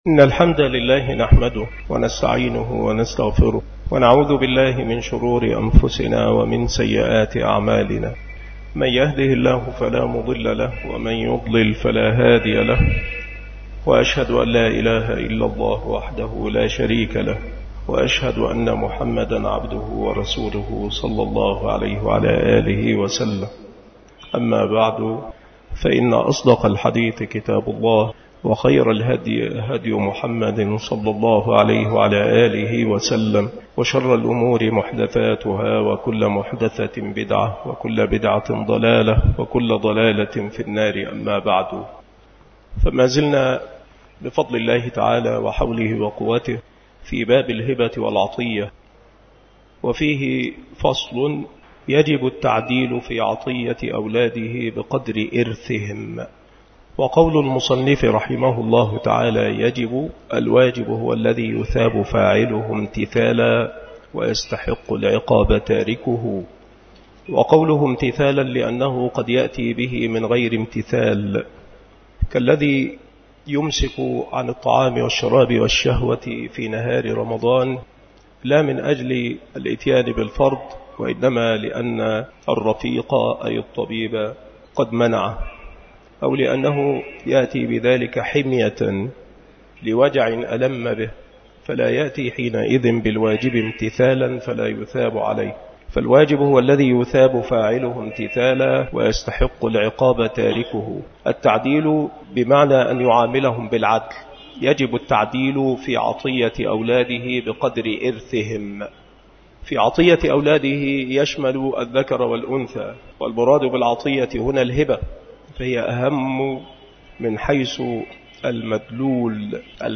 مكان إلقاء هذه المحاضرة بمسجد صلاح الدين بمدينة أشمون - محافظة المنوفية - مصر